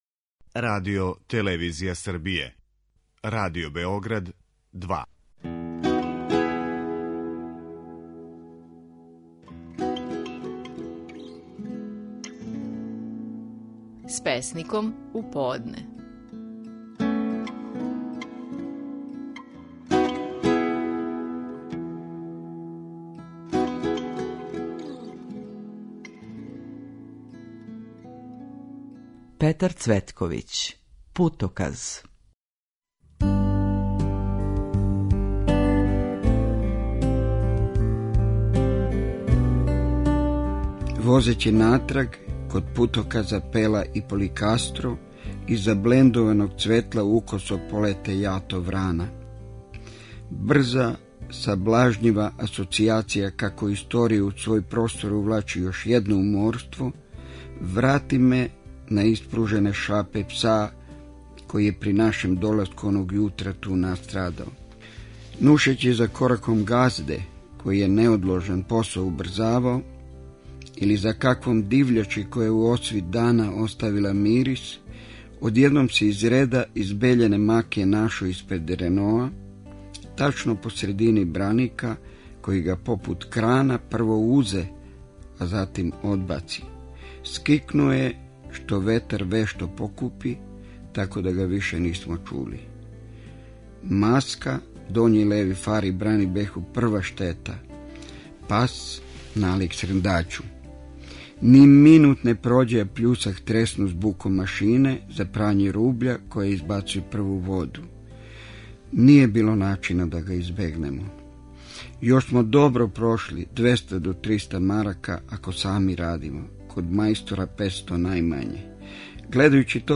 Наши најпознатији песници говоре своје стихове